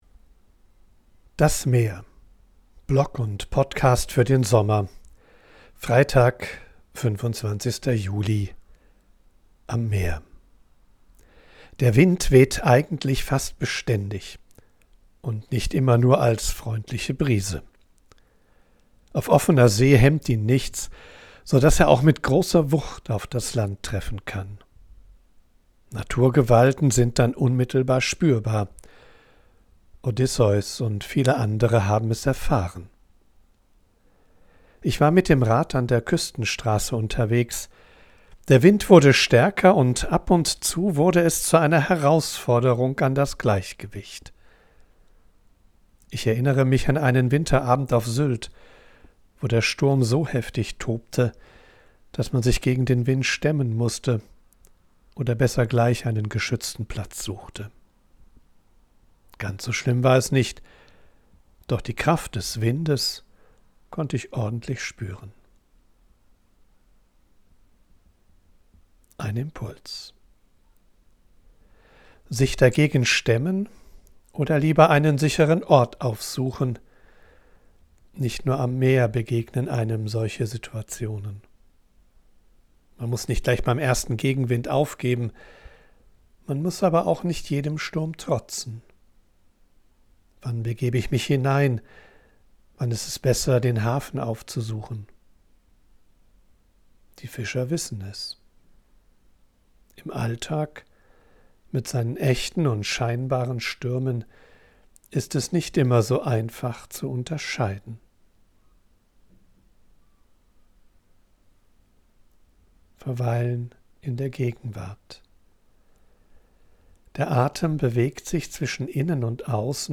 Ich bin am Meer und sammle Eindrücke und Ideen.
von unterwegs aufnehme, ist die Audioqualität begrenzt. Dafür
mischt sie mitunter eine echte Möwe und Meeresrauschen in die